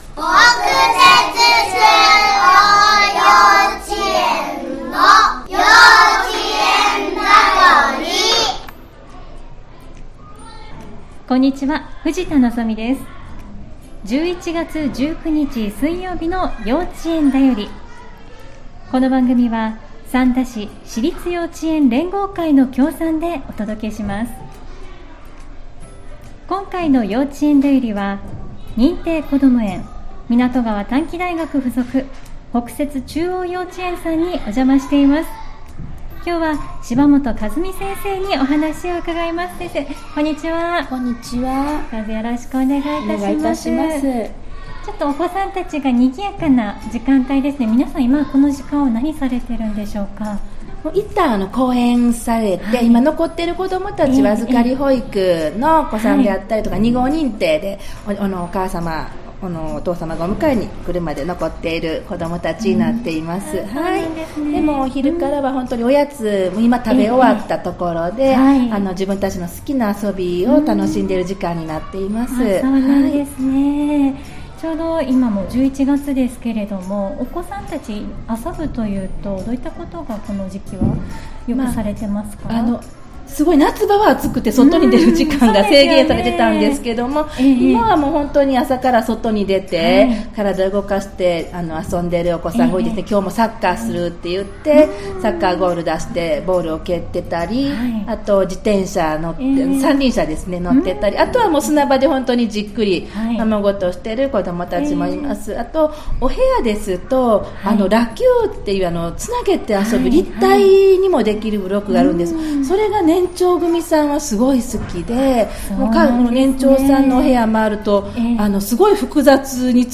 【幼稚園だより】北摂中央幼稚園：先生にインタビュー！｜兵庫県三田市｜ハニーFM - webラジオ｜ポッドキャスト